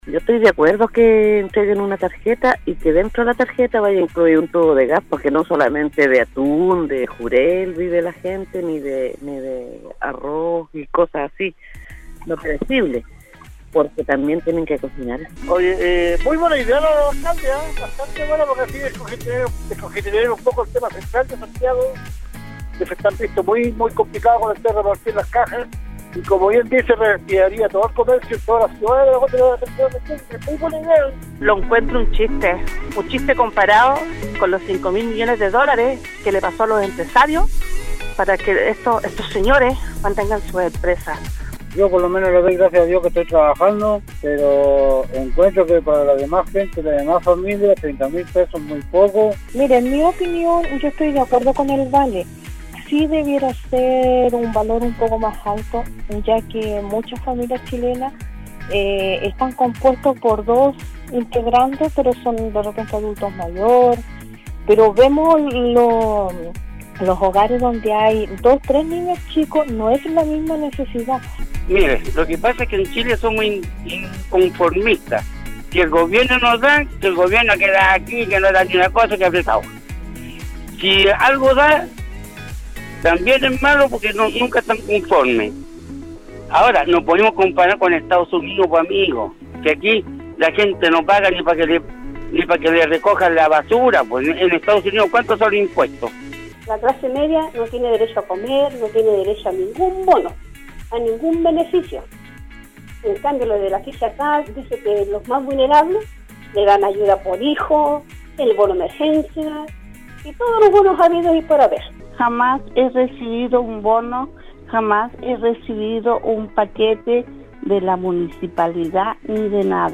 Es el este contexto que en el programa Al Día de Nostálgica, le consultamos a nuestros auditores, cuál era su opinión respecto de esta opción que proponían desde las municipalidades de Atacama.